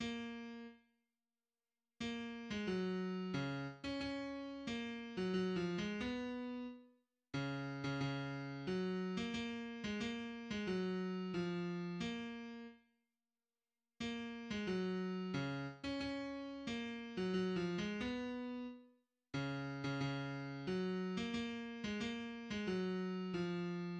{\clef bass \tempo 4=90\key fis \major \set Score.currentBarNumber = #1 \bar "" ais4 r2 ais8. gis16 fis4 cis8 r16 cis' cis'4 ais8. fis16 \tuplet 3/2 { fis8 eis gis } b4 r cis8. cis16 cis4 fis8. ais16 ais8. gis16 ais8. gis16 fis4 eis4 ais4 r2 ais8. gis16 fis4 cis8 r16 cis' cis'4 ais8. fis16 \tuplet 3/2 { fis8 eis gis } b4 r cis8. cis16 cis4 fis8. ais16 ais8. gis16 ais8. gis16 fis4 eis4 }\addlyrics { \set fontSize = #-2 - Geyt ge- dank- en af- fli glen, af gol- - de- ne Flit un shvebt um a vin- te- le a vol- ne - I- ber feld- er un veld- er tse- grin- te un zis, I- ber shti- ber un shte- te- lekh shey- - dem- shtil.